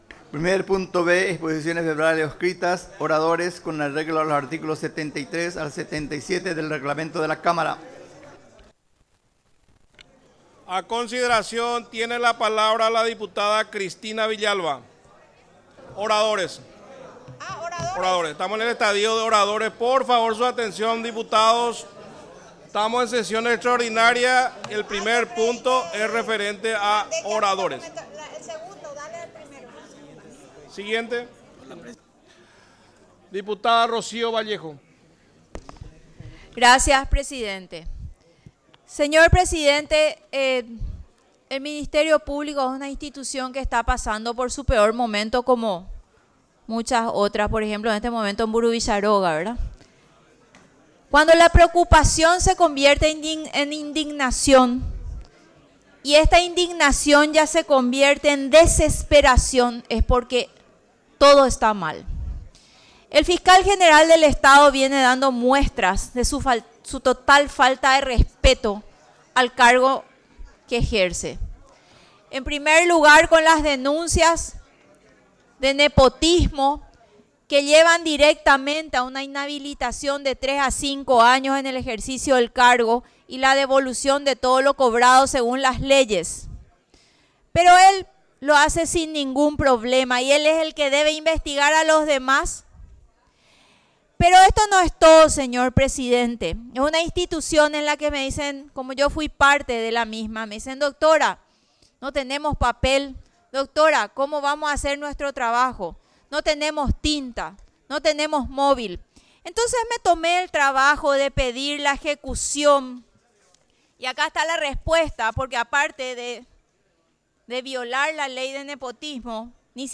Sesión Extraordinaria, 16 de setiembre de 2025
Exposiciones verbales y escritas